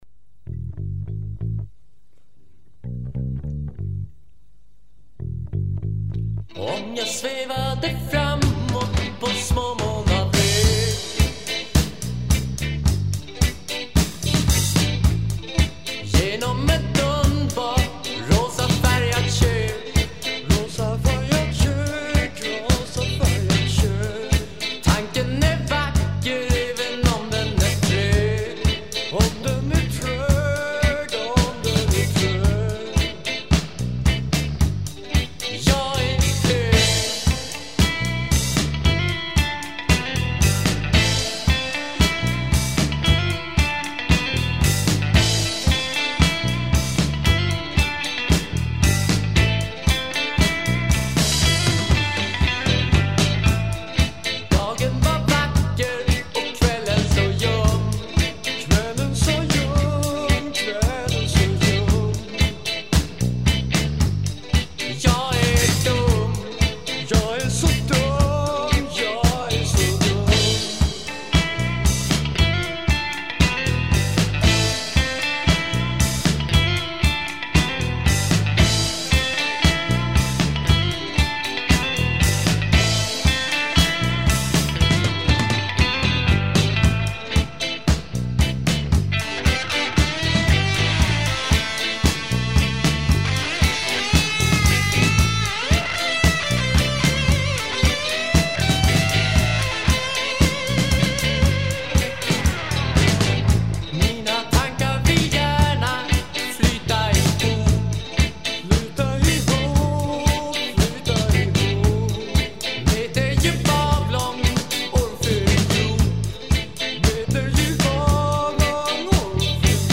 Drums
Guitar, Voice
Bass